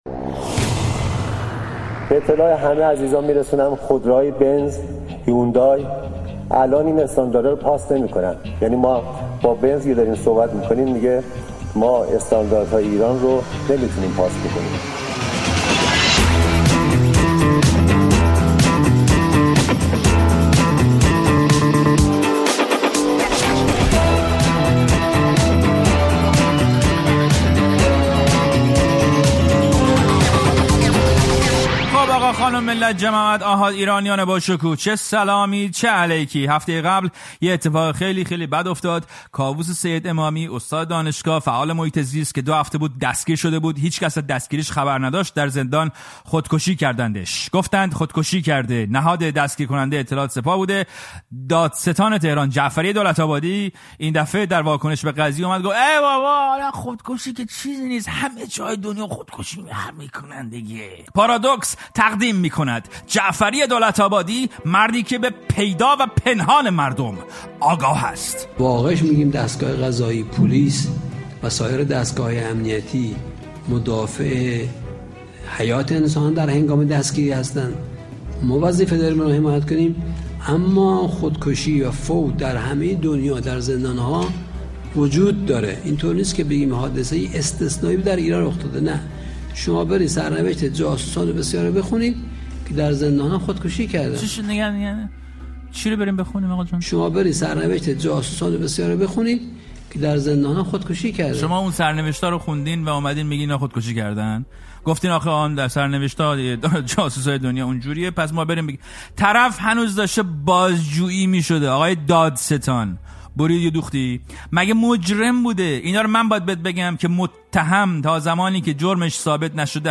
پارادوکس با کامبیز حسینی؛ گفت‌وگو
«پارادوکس» هر هفته روزهای جمعه در ساعت ۹ شب به وقت تهران از رادیو فردا پخش می‌شود.